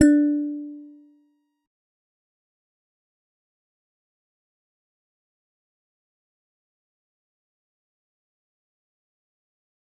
G_Musicbox-D4-mf.wav